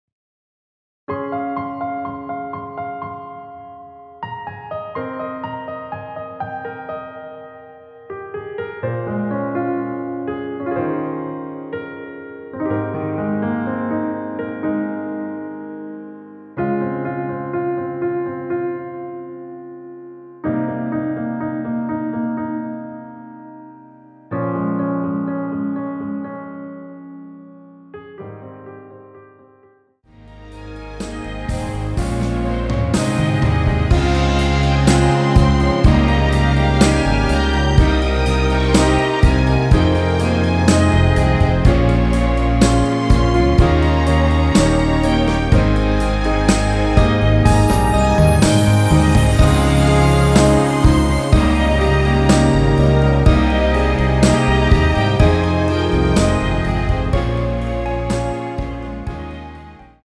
원키 MR입니다.
Ab
앞부분30초, 뒷부분30초씩 편집해서 올려 드리고 있습니다.